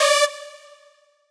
synth2_5.ogg